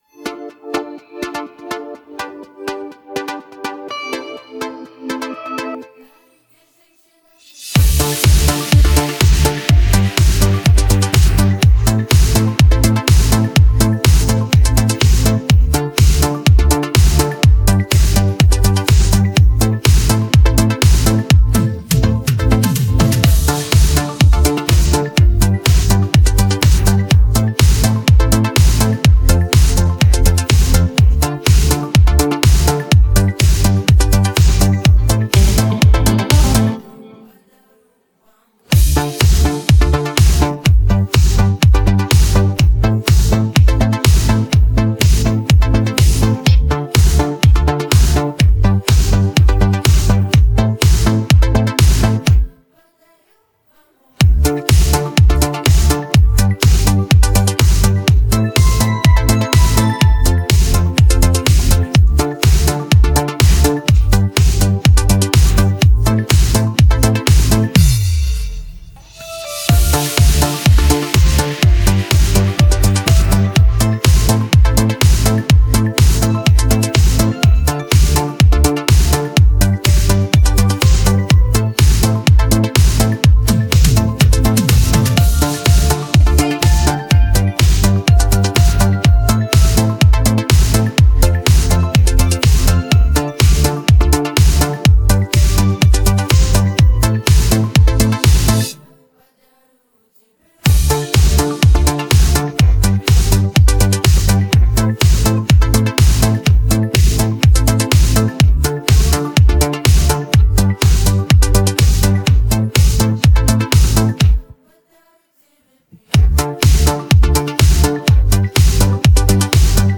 Российский поп